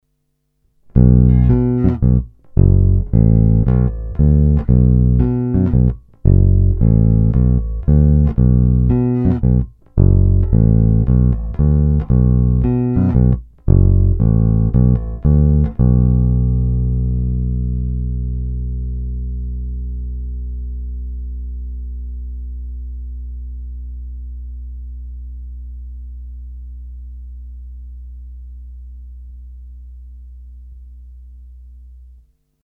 Není-li uvedeno jinak, následující nahrávky jsou vyvedeny rovnou do zvukové karty a kromě normalizace ponechány bez zásahů. Tónová clona byla vždy plně otevřená.
Snímač u krku